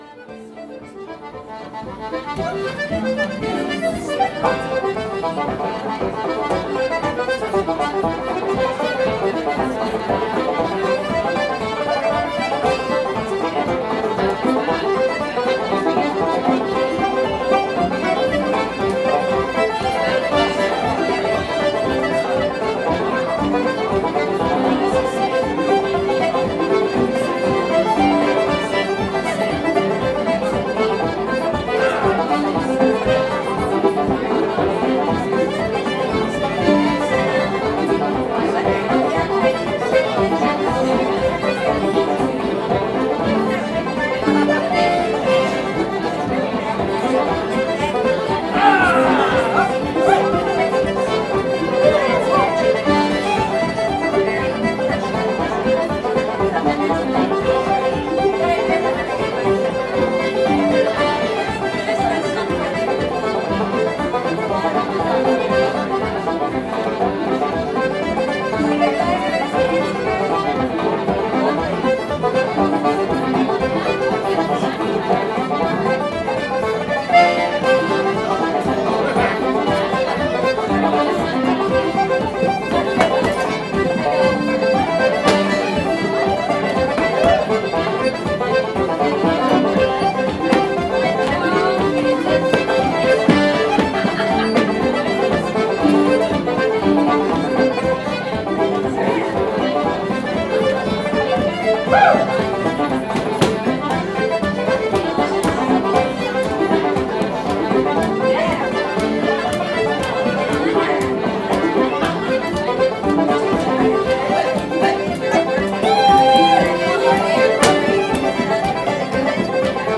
McGrath's Pub Session excerpt during Catskills Irish Arts Week.